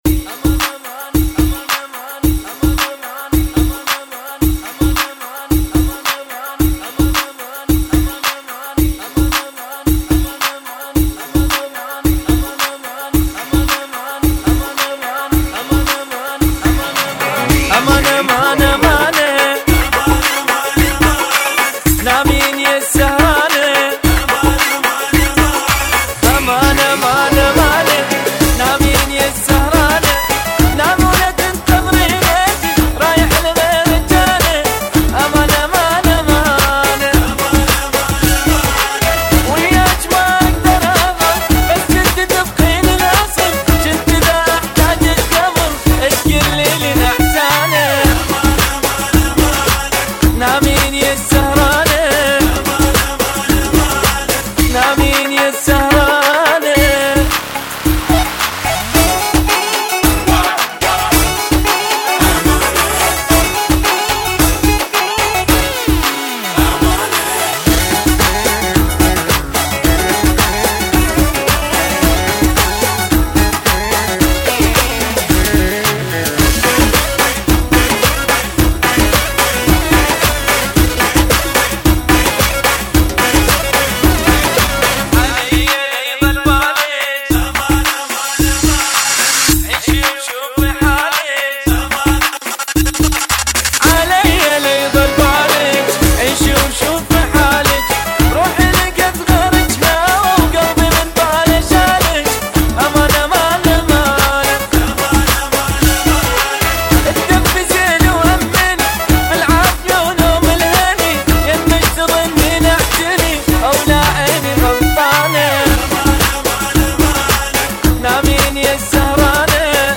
110 bpm